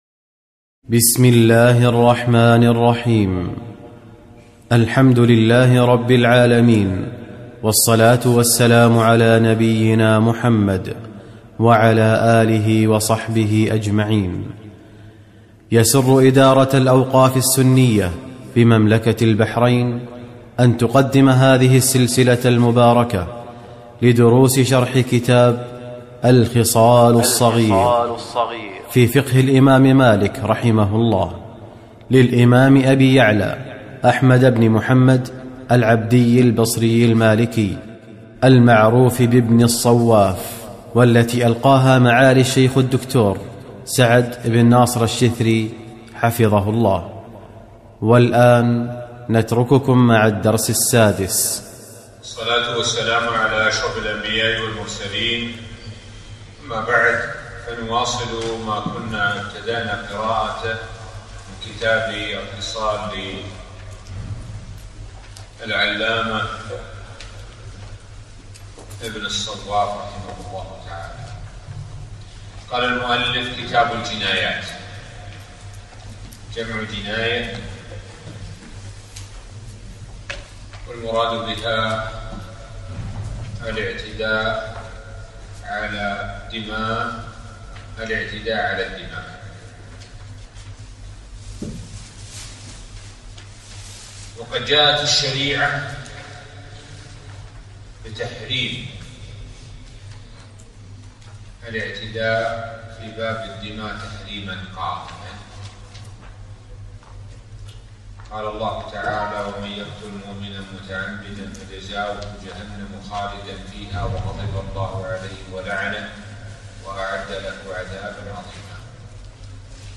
الدرس (6): كتاب الجنايات – كتاب الجامع السابق التالى play pause stop mute unmute max volume